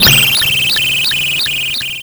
BirdTransmission.wav